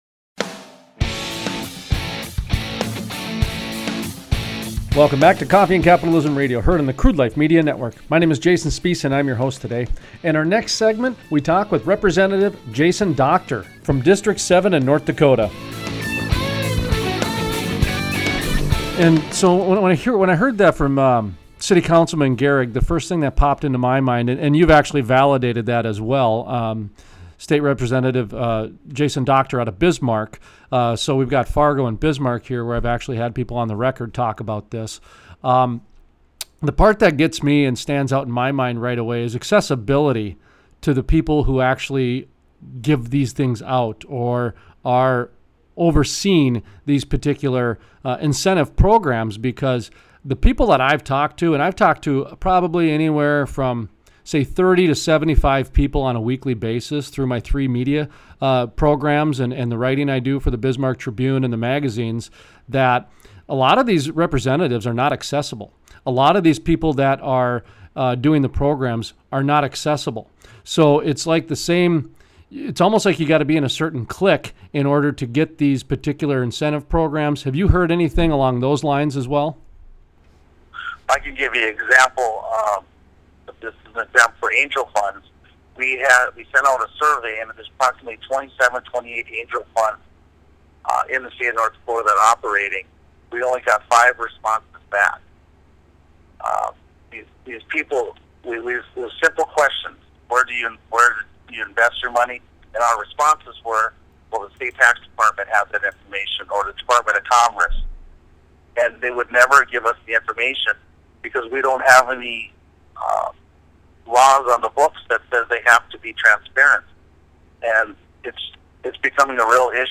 Interviews: Representative Jason Dockter, District 7 ND Shares his thoughts on the lack of accountability in government Angel Funds and tax incentives